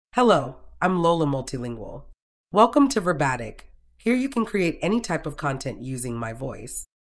FemaleEnglish (United States)
Lola MultilingualFemale English AI voice
Lola Multilingual is a female AI voice for English (United States).
Voice sample
Listen to Lola Multilingual's female English voice.
Female
Lola Multilingual delivers clear pronunciation with authentic United States English intonation, making your content sound professionally produced.